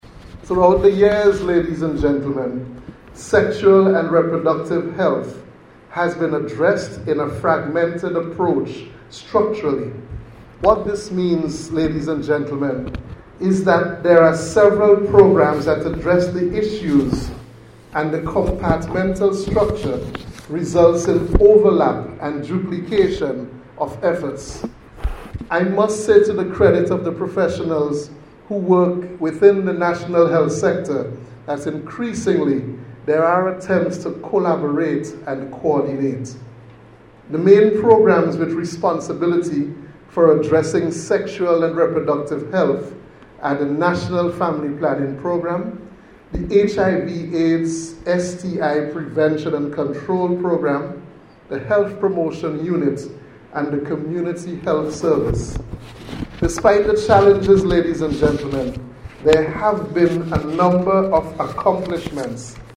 Mr. DeShong was delivering remarks at a lecture focusing on Sexual and Reproductive Health last night at Frenches House.